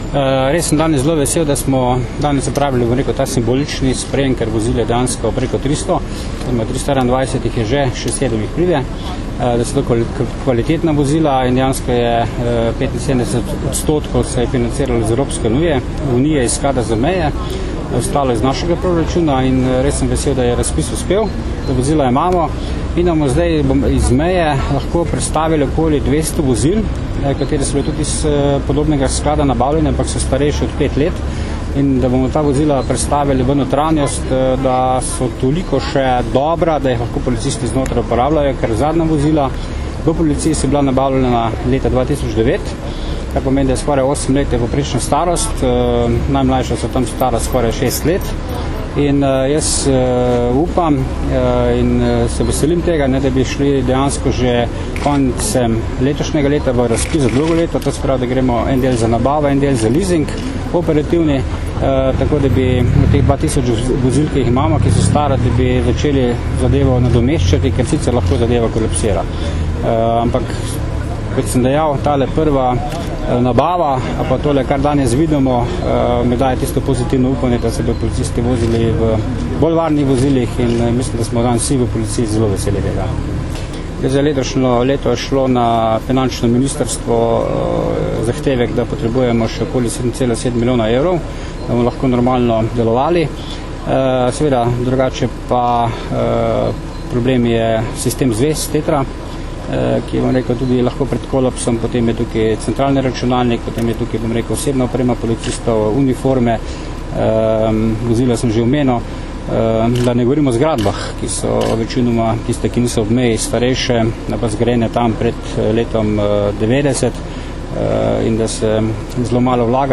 Zvočni posnetek izjave generalnega direktorja policije Stanislava Venigerja (mp3)